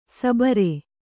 ♪ sabare